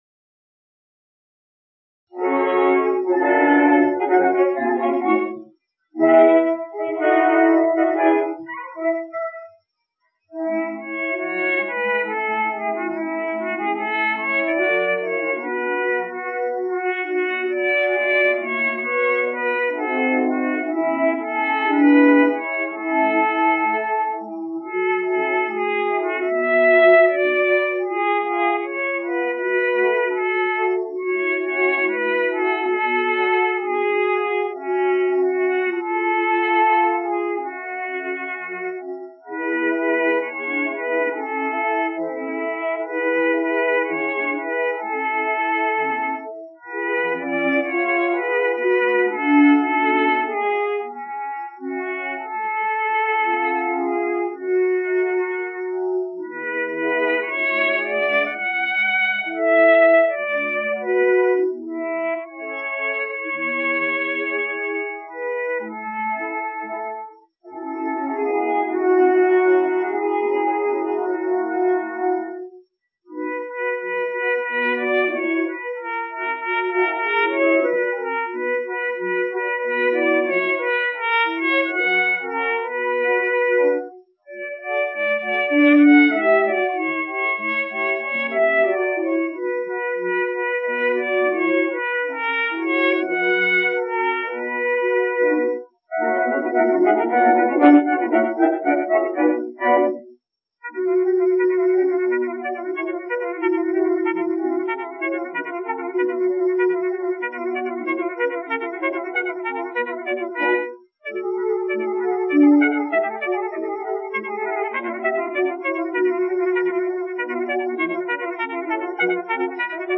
cornetist